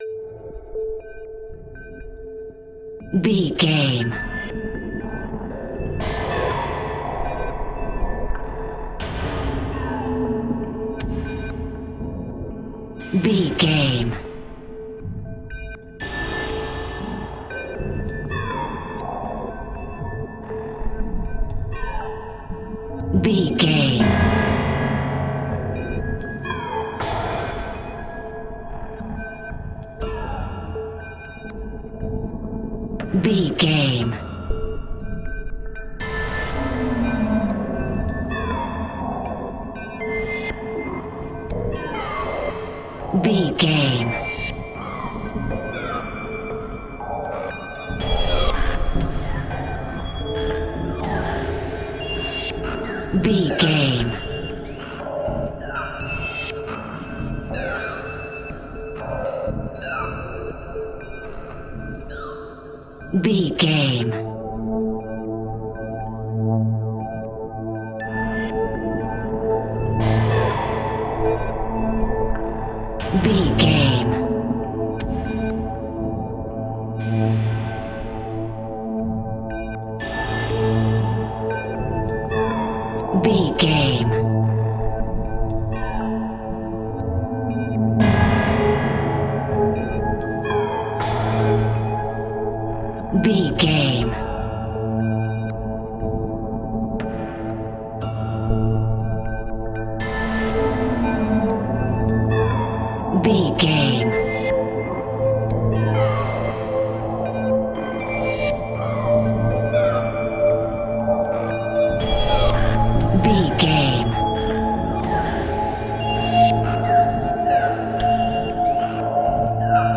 Aeolian/Minor
ominous
dark
suspense
eerie
piano
percussion
strings
synthesiser
Horror Synths